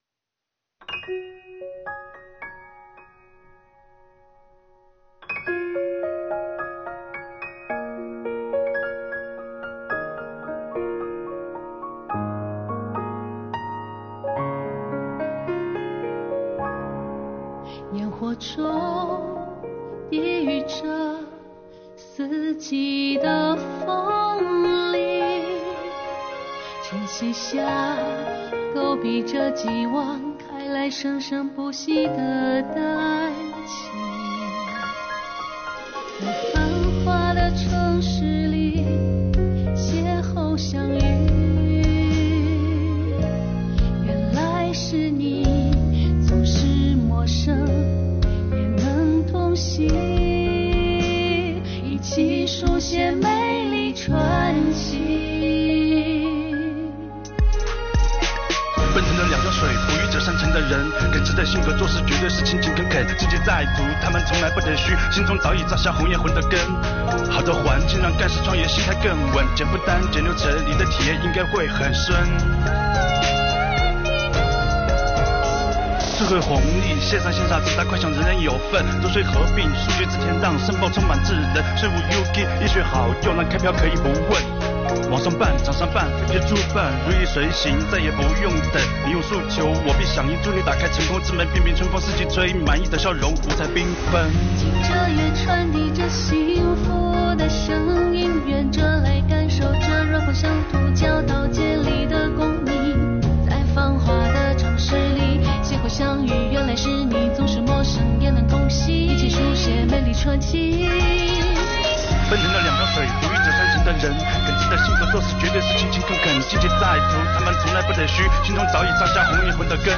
标题: 中国风《山与云》讲述你我的故事
由重庆市税务局干部职工自主创作和演唱的中国风歌曲《山与云》新鲜出炉。歌曲围绕“便民办税春风行动”和优化税收营商环境举措，将纳税人缴费人与税务部门的关系巧用“山、云”比喻，唱出了云山环绕共建和谐美丽社会的努力与决心，唱出了重庆“近者悦 远者来”优良的营商环境。